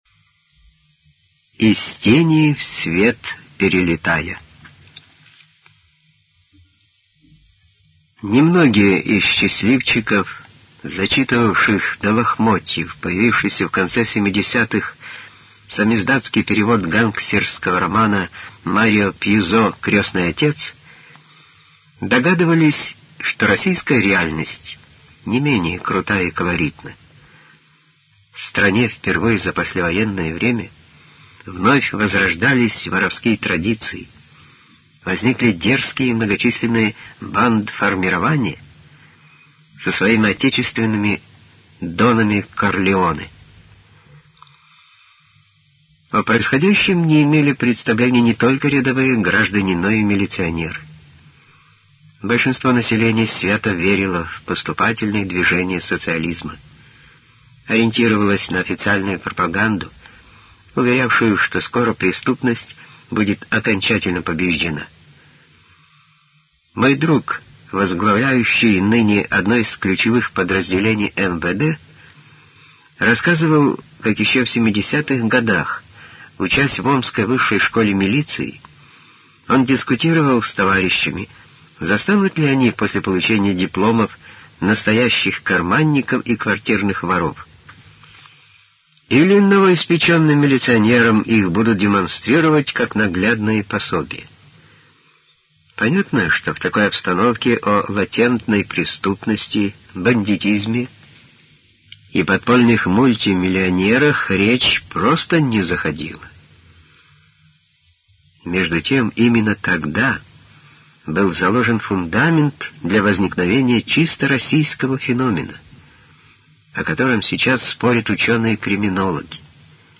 Аудиокнига Николай Модестов «Москва бандитская» - Из тени в свет перелетая